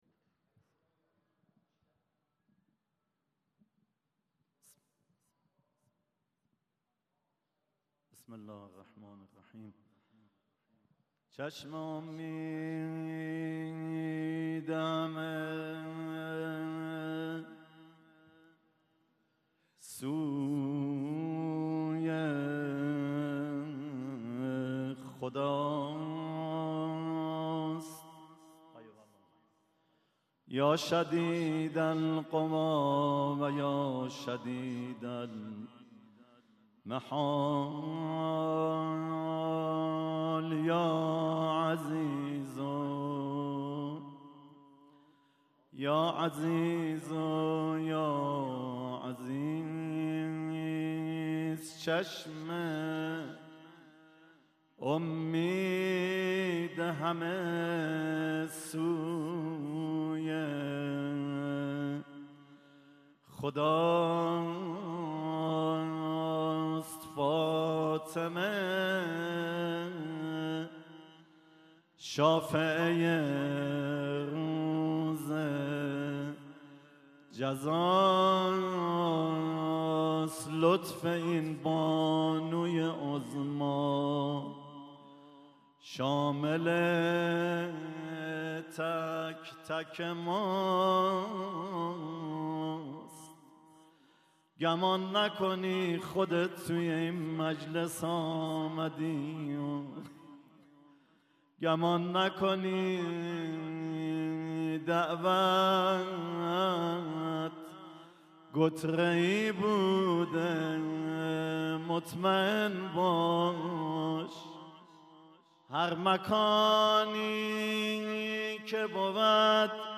مراسم دهه آخر صفر در مسجد امام خمینی بازار تهران